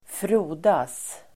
Ladda ner uttalet
frodas verb (om växter; även bildligt), flourish , thrive Grammatikkommentar: x & Uttal: [²fr'o:das] Böjningar: frodades, frodats, frodas Definition: växa snabbt Exempel: fördomarna frodas (prejudices are flourishing)